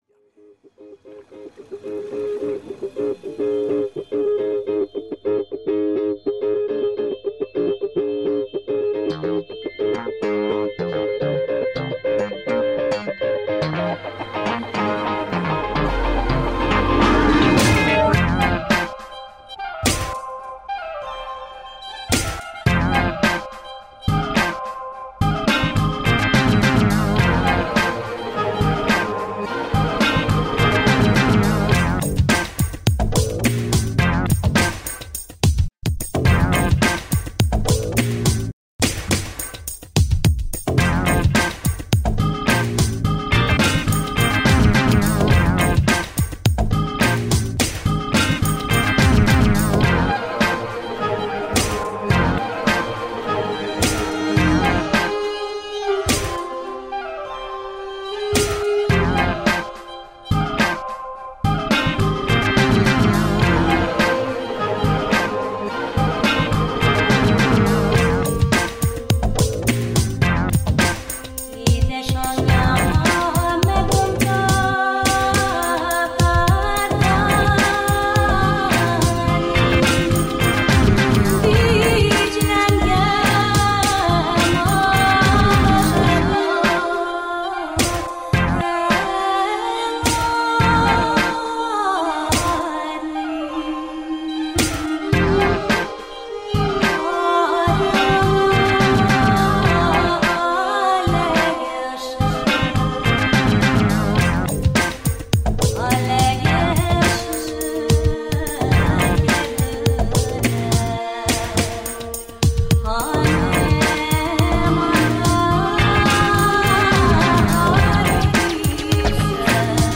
Electronica, Rock, Alt Rock, Remix